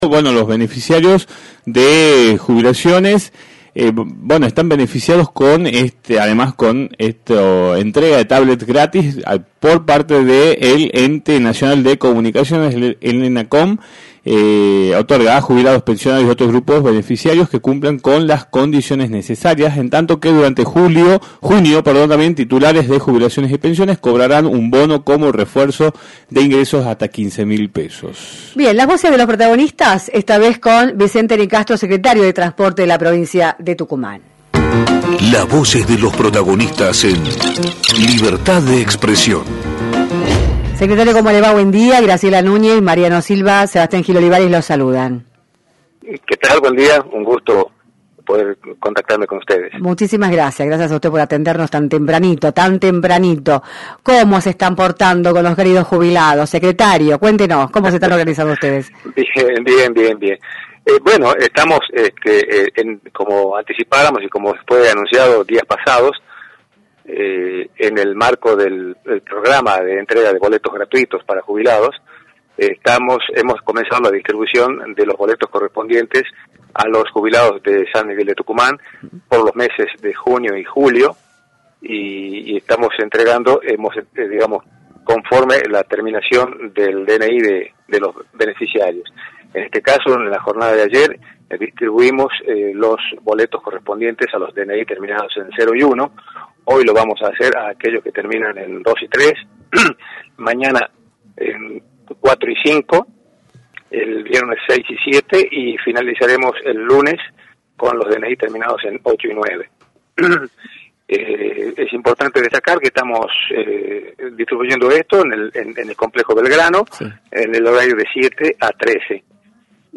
Vicente Nicastro, Secretario de Transporte, abordó en “Libertad de Expresión”, por la 106.9, los detalles a tener en cuenta con respecto a la entrega de boletos gratuitos para los jubilados de la provincia.